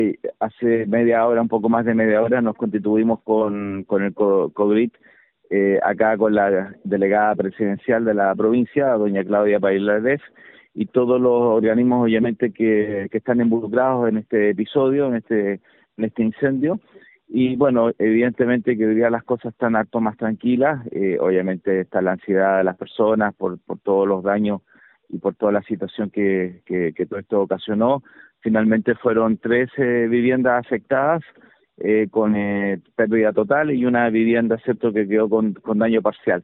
Durante esta mañana, se realizó una sesión del Comité de Gestión y Riesgo de Desastres, COGRID, con la participación de las autoridades locales para analizar los alcances de esta emergencia, según explicó el Alcalde José Luis Muñoz, fueron tres las viviendas afectadas en su totalidad, y una de manera parcial.